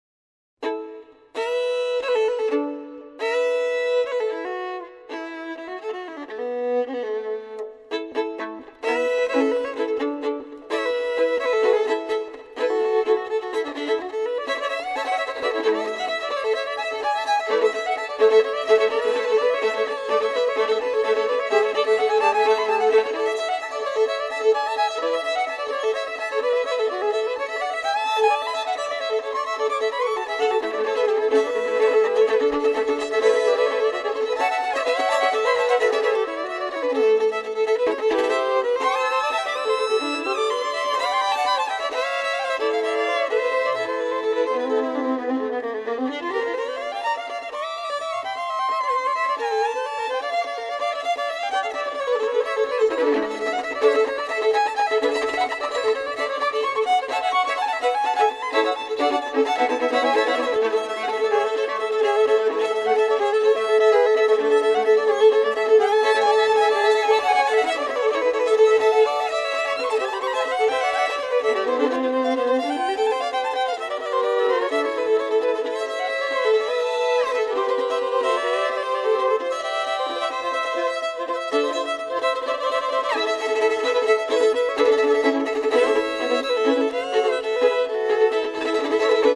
Dixie Jazz / Compilation